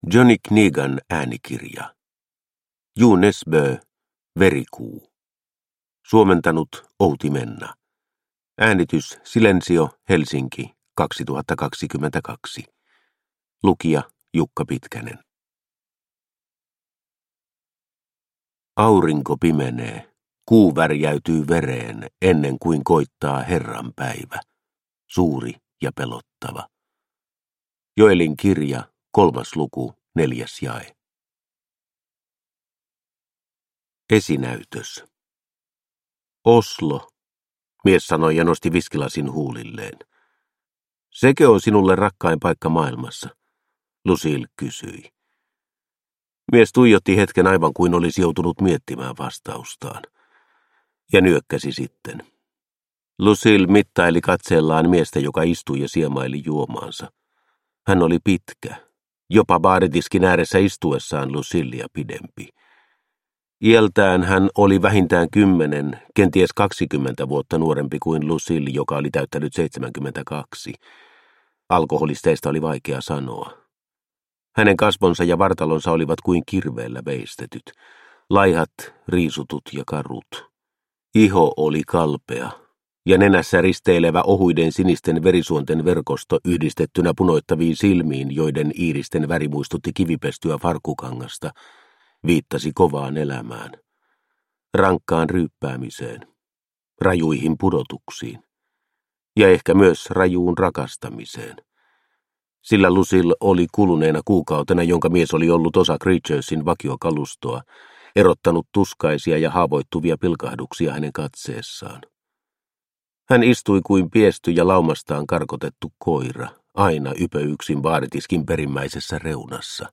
Verikuu – Ljudbok – Laddas ner